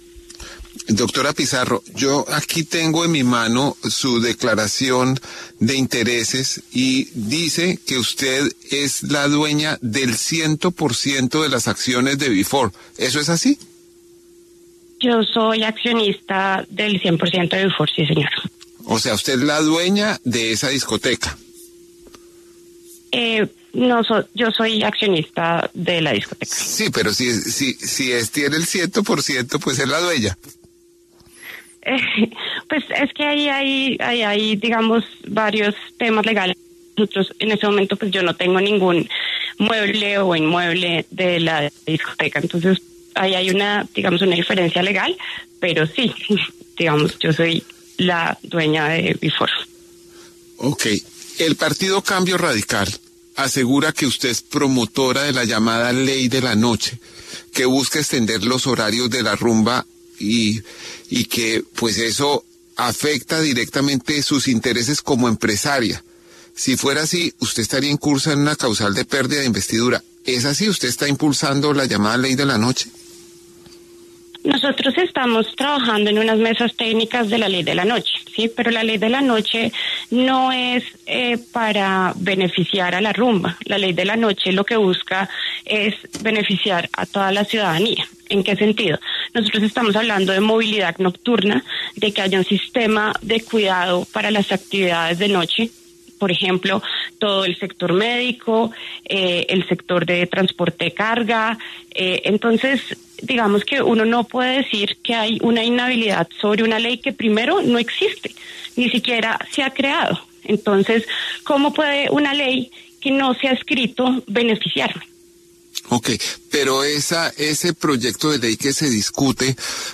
La representante María del Mar Pizarro aseguró en conversación con El Reporte Coronell que la denominada Ley de la Noche ni siquiera se está discutiendo porque no es un proyecto de ley.